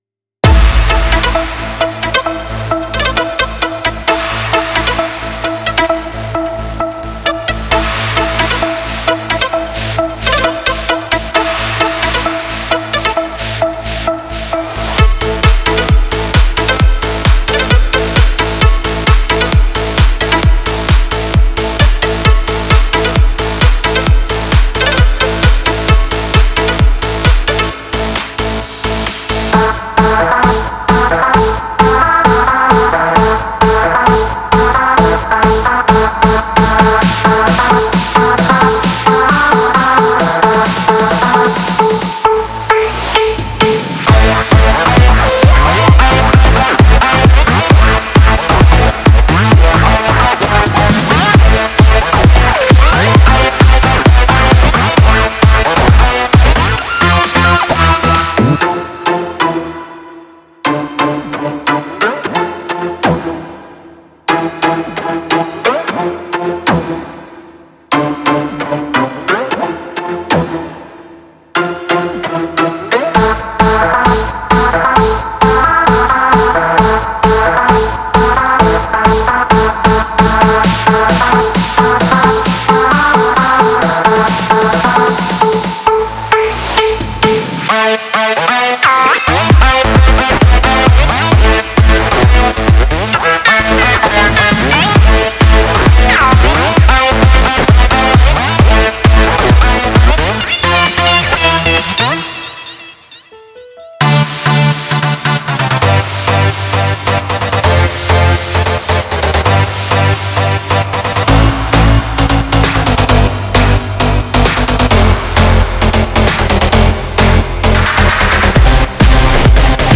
כאן העלה את כל הדברים שאני בונה ומתכנת באורגן, ימאה כמובן
חזרתי קצת לאלקטרוני